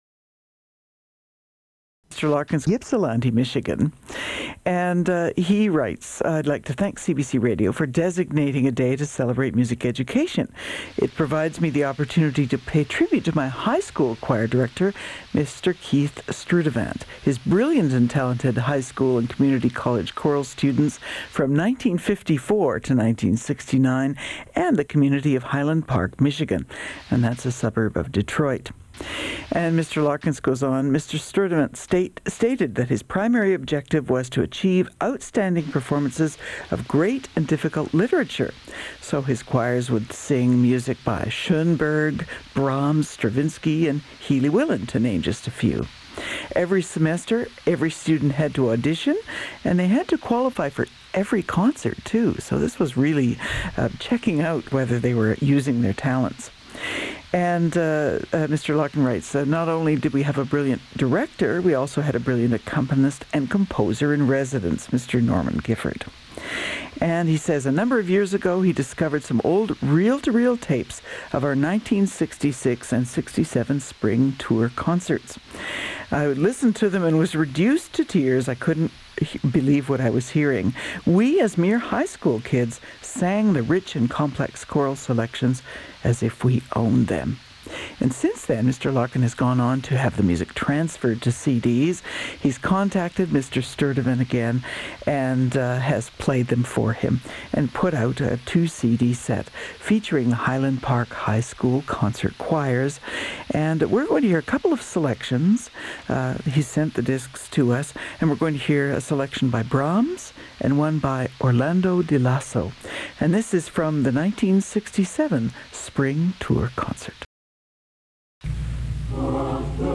The segment was taped from the radio so at times you will hear some static.